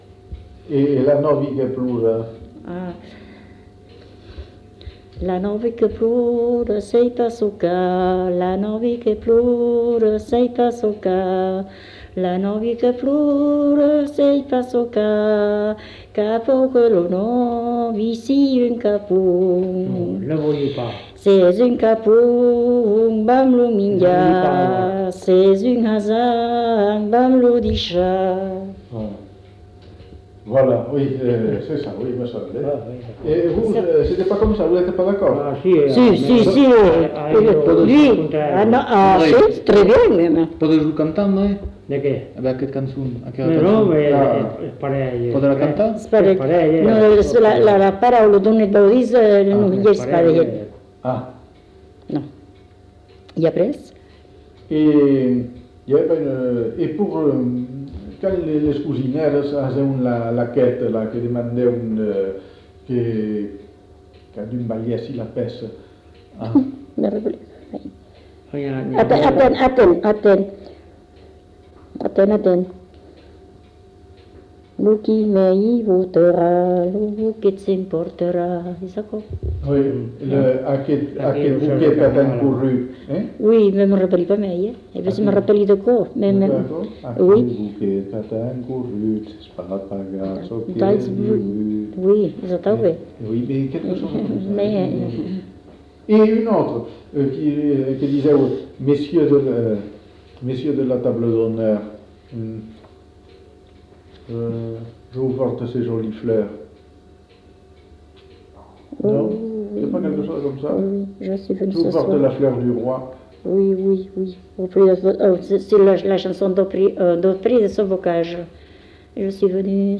Aire culturelle : Bazadais
Lieu : Cazalis
Genre : chant
Effectif : 1
Type de voix : voix de femme
Production du son : chanté